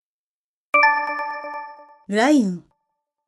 シンプルな電子ベル音の後に女性の声でラインと言います。